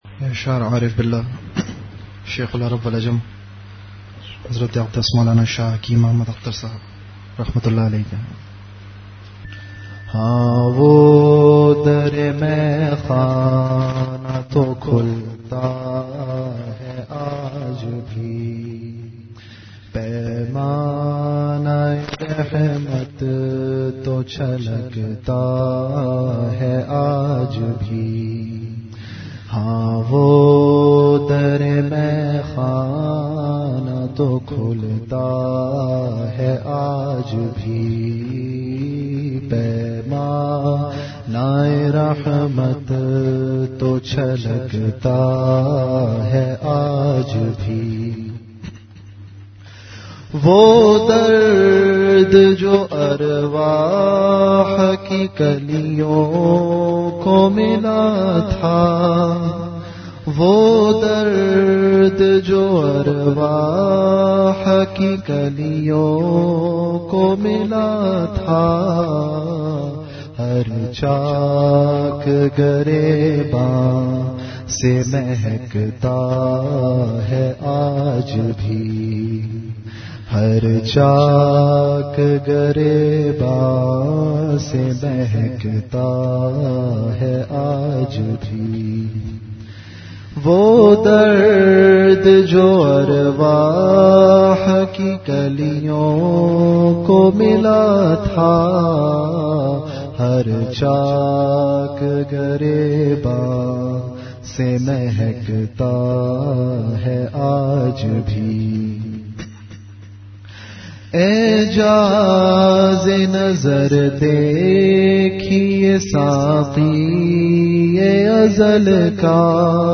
An Islamic audio bayan
Delivered at Home.
Majlis-e-Zikr · Home Islah Ki Fikr Ho To Rastay Khul Jatay Hain